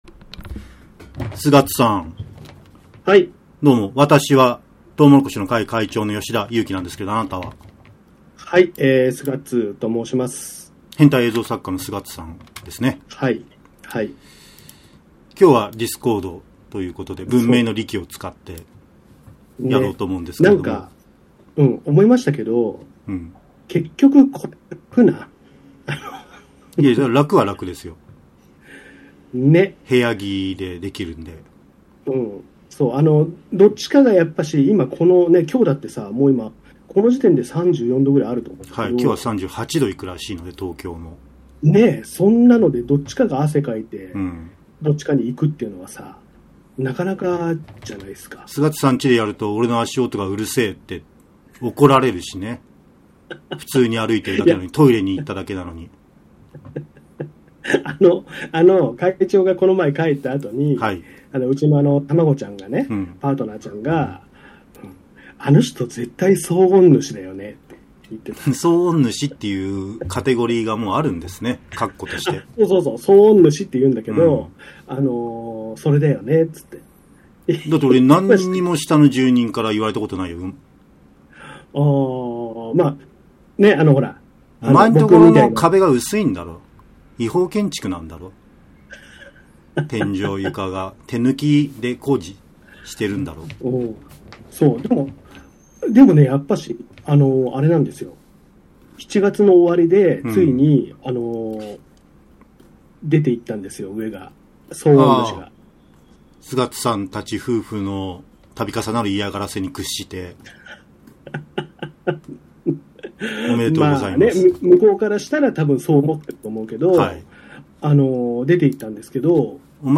猛暑よりもさらに暑苦しく、おじさんたちが平日の午前から社会を憂えて大討論を交わします！